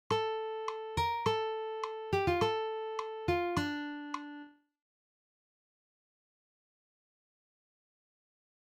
PLAY] (A-Bb-A-G-F-A-F-D)
It sounds nondescript enough that it might be the sort of thing that any number songwriters might randomly create.
The melody starts on A, but when you listen to the entire fragment, you get a strong sense of D minor, since it ends with 3 notes from the D minor triad played from high to low: A-F-D.